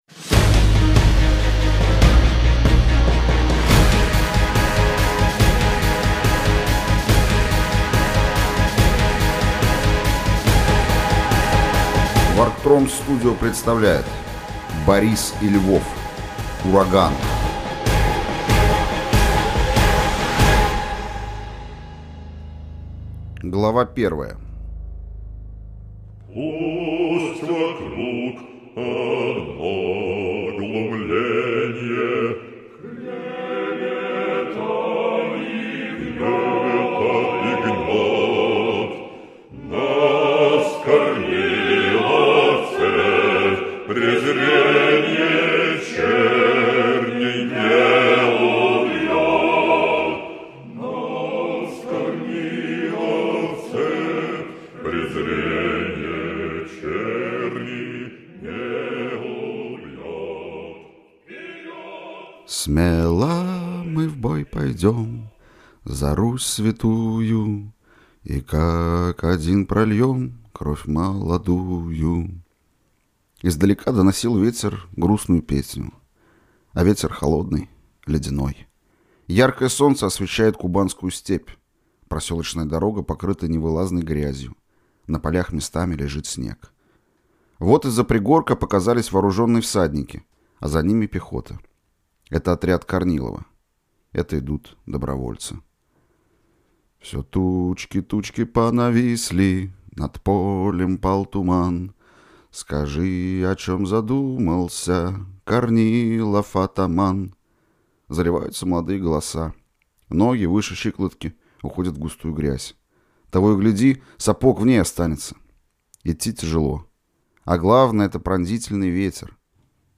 Аудиокнига Ураган | Библиотека аудиокниг